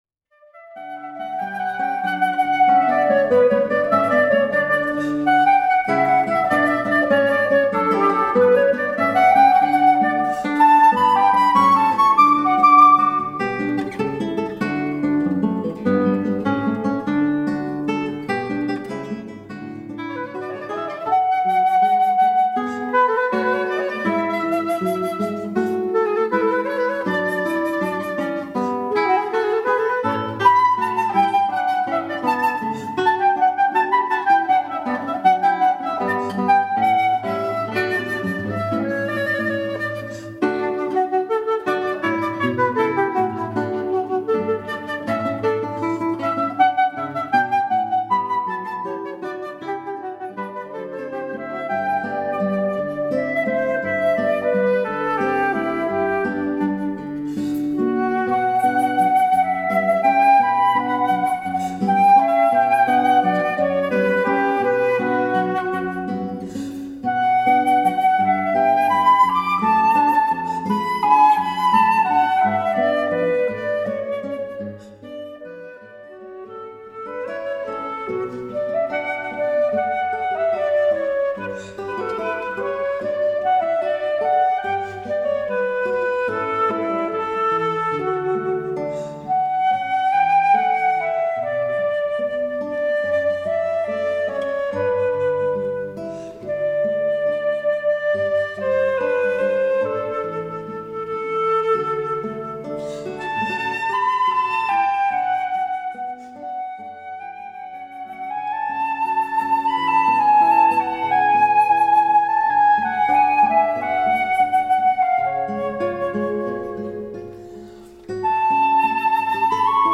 flute and guitar duo
classical guitar
Music Sample: